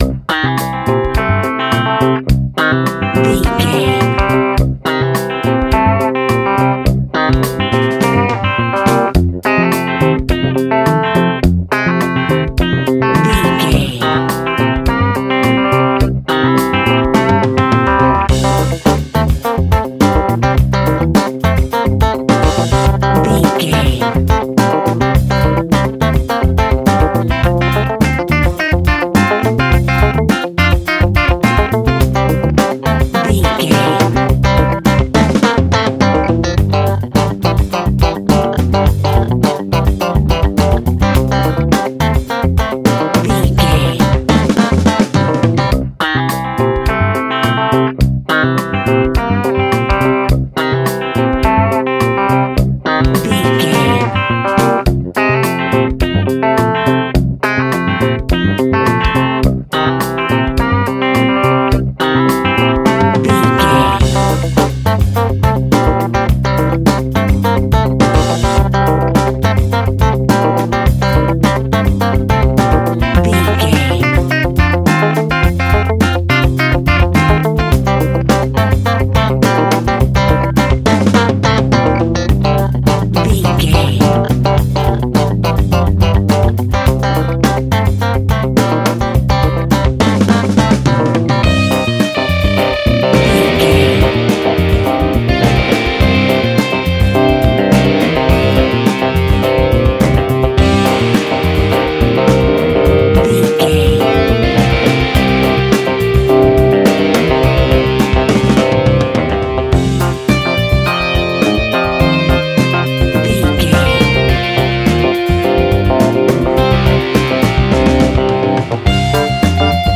Ionian/Major
groovy
funky
lively
electric guitar
electric organ
drums
bass guitar
saxophone
percussion